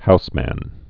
(housmăn, -mən)